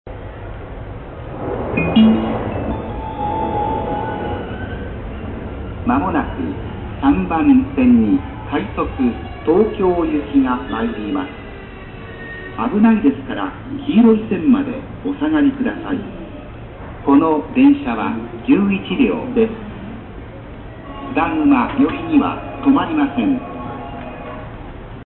接近放送東京行き11両B線東京行きの接近放送です。
津田沼寄りには停車しない案内をしますが、「津田沼」と「寄りには」がツギハギなのが特徴です。
for-tokyo-11c.mp3